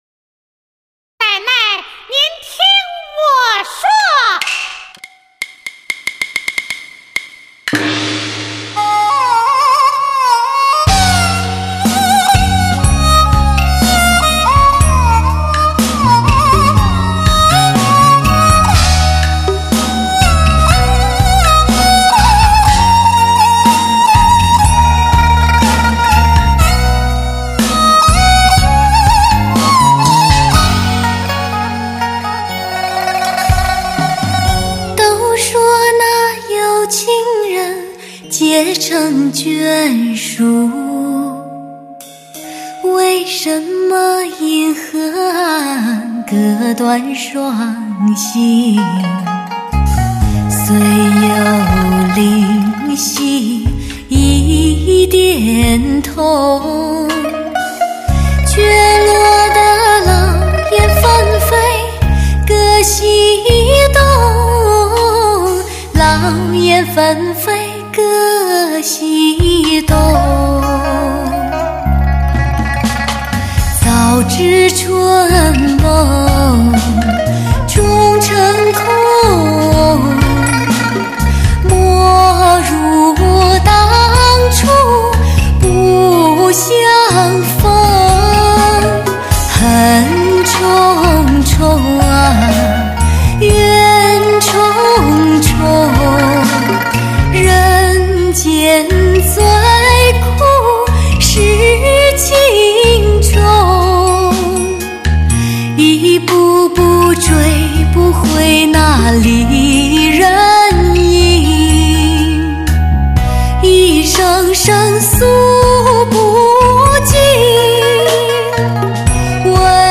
类型: 汽车音乐
十五首极致人声，震撼音效，迷惑你的耳朵。完美配乐，超广音场，天籁声线，独立于流行之外的发烧